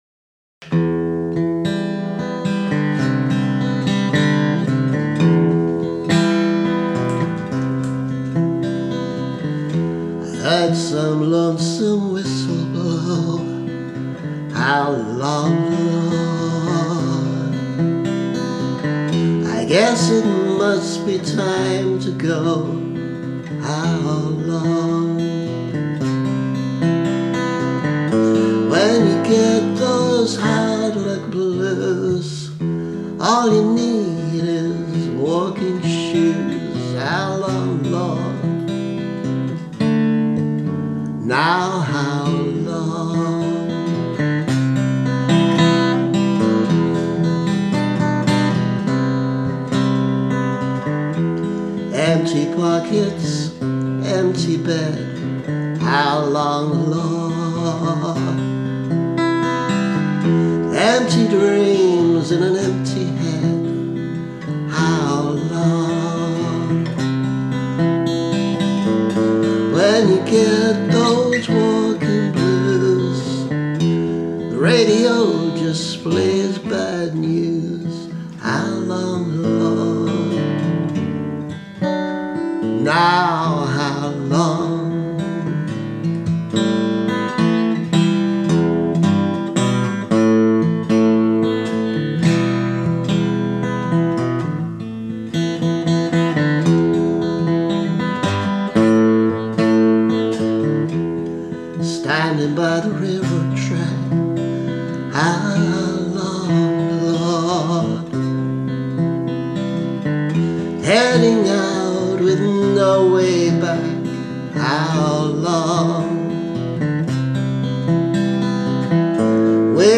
A very young song with an obvious blues influence, though not a conventional blues structure.